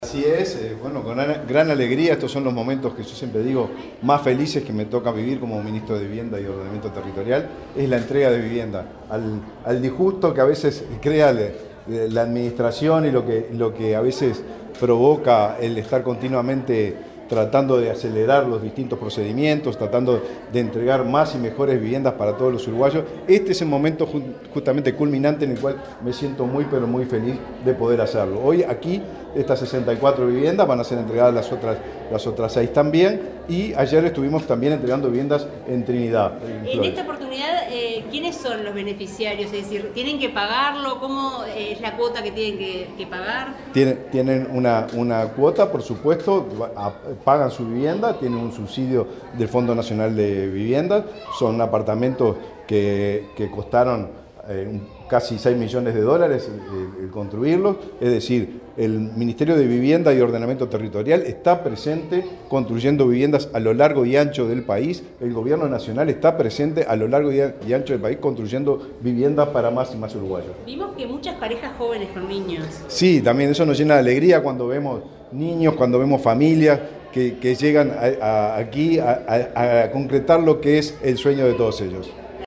Declaraciones del ministro de Vivienda, Raúl Lozano
Declaraciones del ministro de Vivienda, Raúl Lozano 12/12/2023 Compartir Facebook X Copiar enlace WhatsApp LinkedIn Este martes 12, el ministro de Vivienda, Raúl Lozano, participó en la entrega de 70 viviendas en las modalidades de compra y alquiler con opción de compra, en un edificio de avenida del Libertador, en Montevideo. Luego dialogó con la prensa.